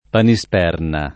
Panisperna [ pani S p $ rna ]